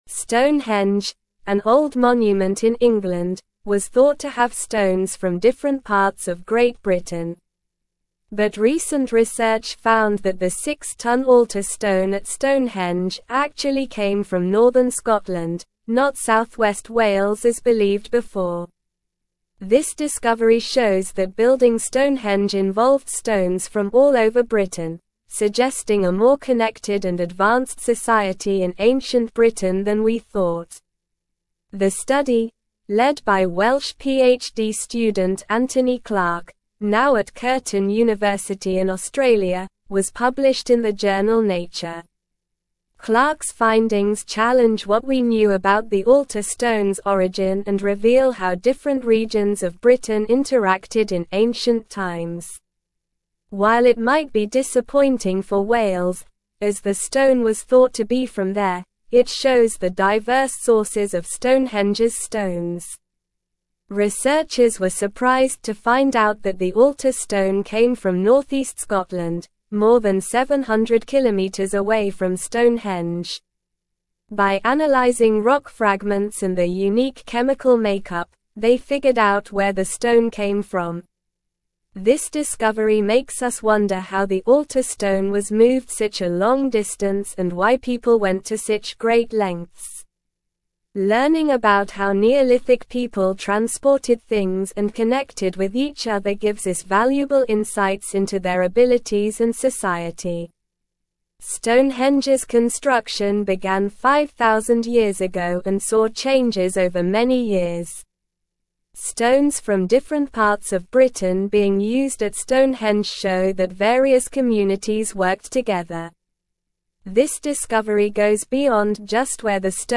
Slow
English-Newsroom-Upper-Intermediate-SLOW-Reading-Stonehenge-Altar-Stone-Originates-from-Scotland-Not-Wales.mp3